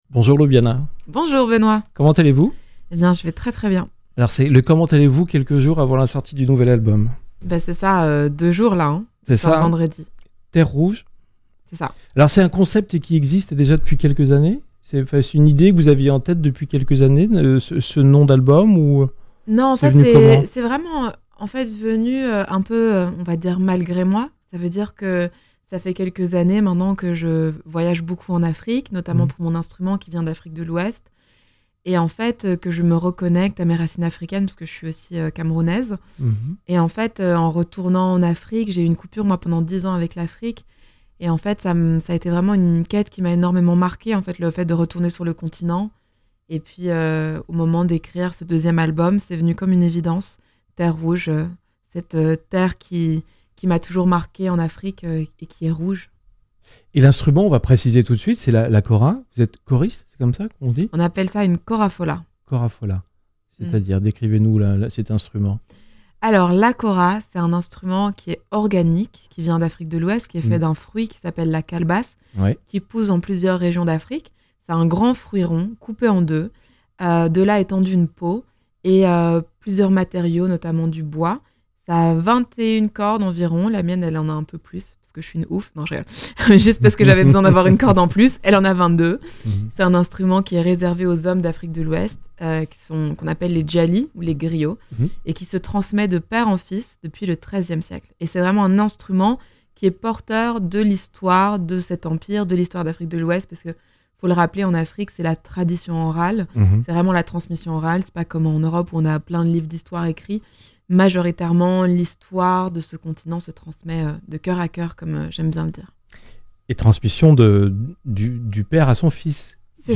Interview Jazz Radio x Docks Live Sessions